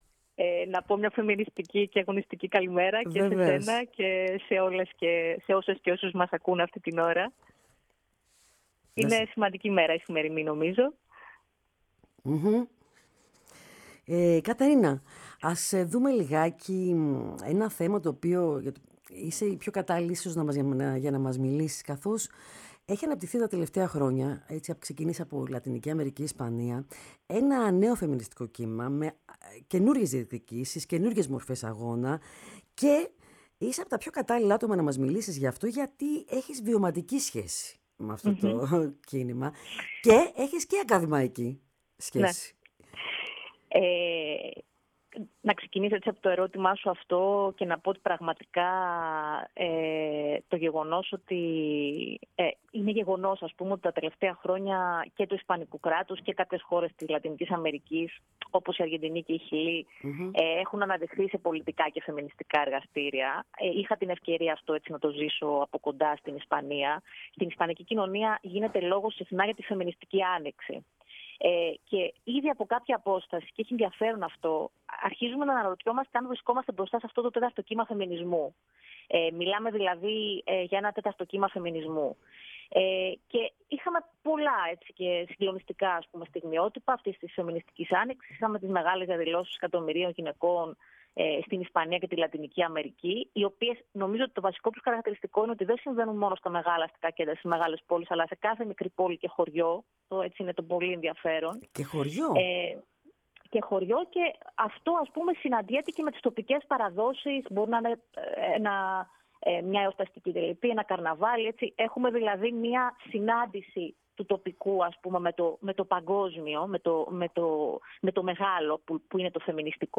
Ακούστε την συνέντευξη στο ηχητικό της ανάρτησης, Η ΦΩΝΗ ΤΗΣ ΕΛΛΑΔΑΣ Αποτυπωμα Πολιτισμός Συνεντεύξεις